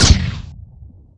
m41a-fire3.wav